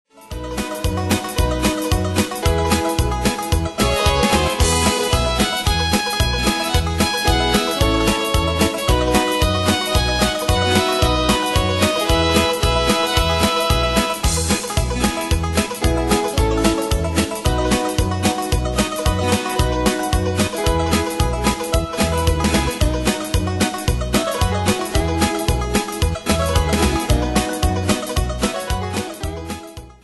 Style: Country Ane/Year: 1995 Tempo: 113 Durée/Time: 2.19
Pro Backing Tracks